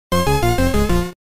tendo game over